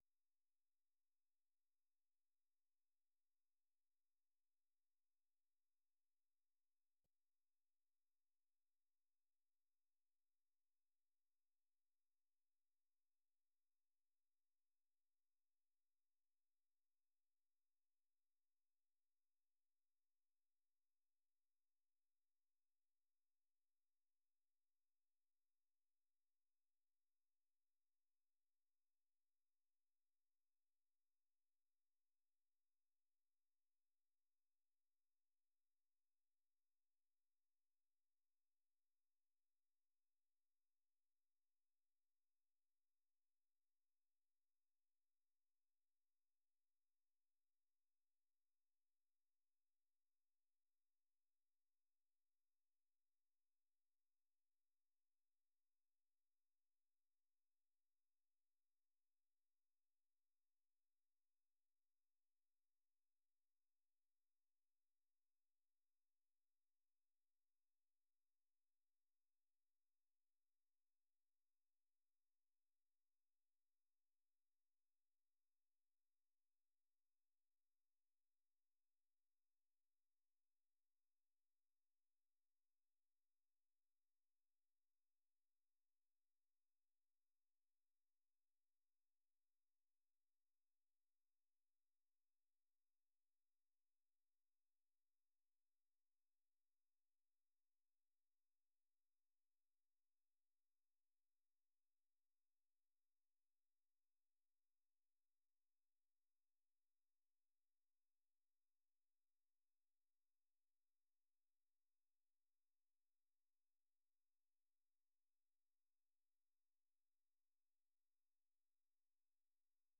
VOA 한국어 간판 뉴스 프로그램 '뉴스 투데이', 3부 방송입니다.